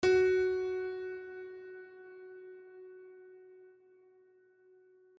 HardPiano